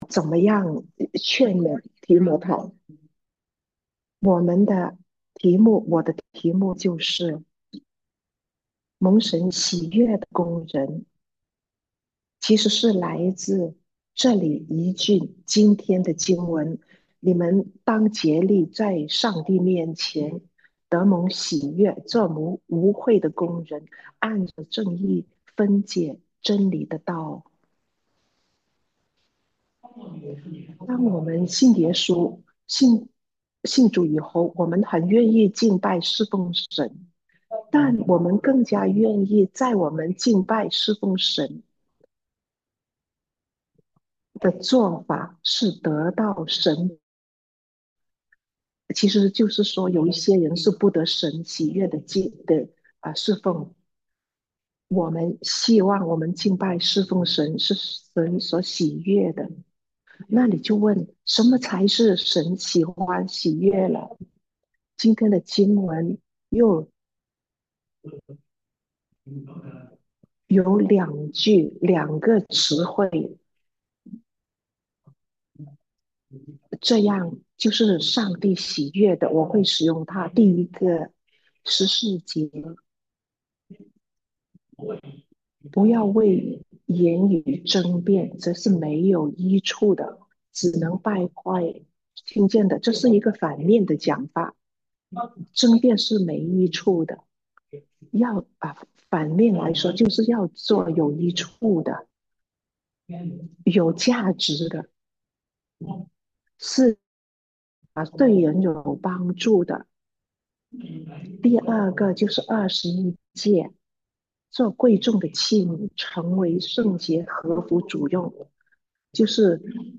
蒙神喜悅的工人 – 普通話傳譯